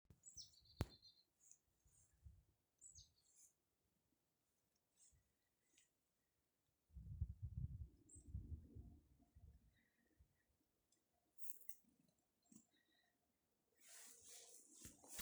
гаичка, Poecile palustris
Ziņotāja saglabāts vietas nosaukumsBauskas nov, Valle